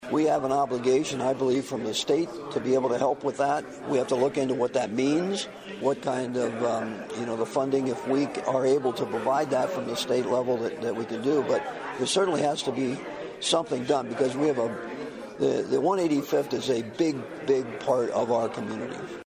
BOB HENDERSON SAYS HE AND OTHER STATE LAWMAKERS WILL FIGHT FOR THAT: